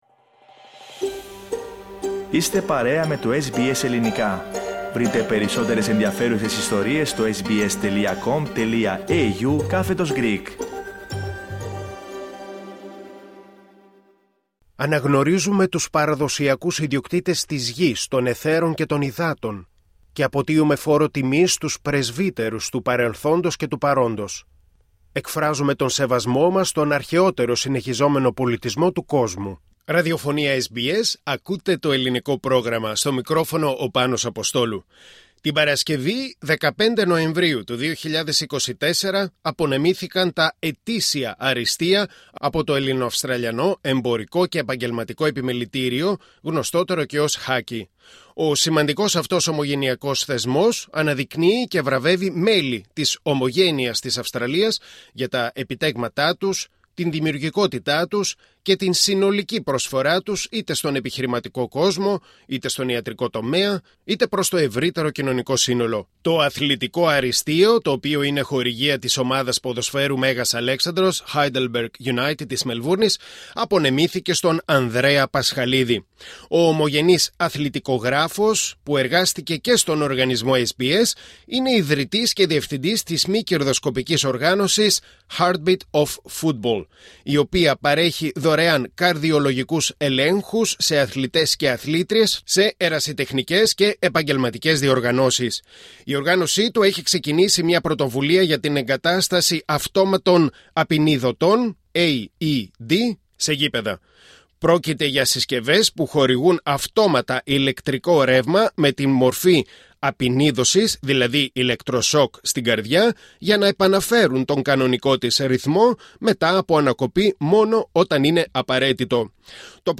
Το SBS Greek βρέθηκε στην βραδιά απονομής των Αριστείων του HACCI